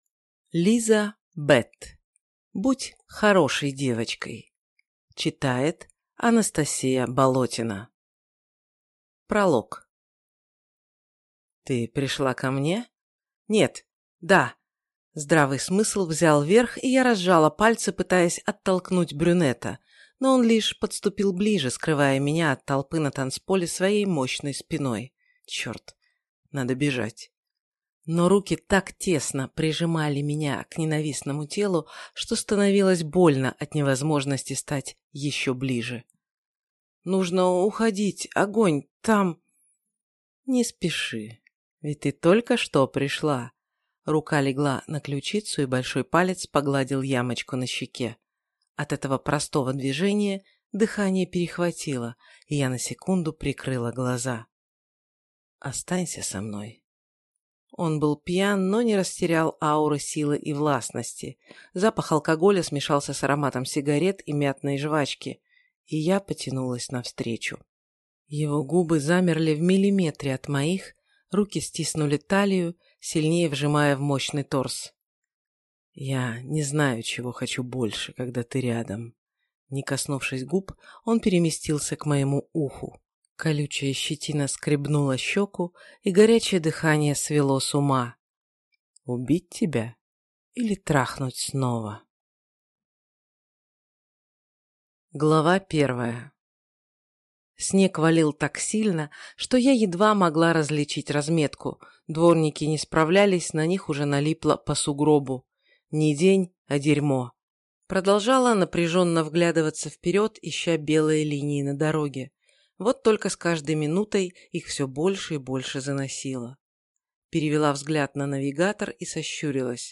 Аудиокнига Будь хорошей девочкой | Библиотека аудиокниг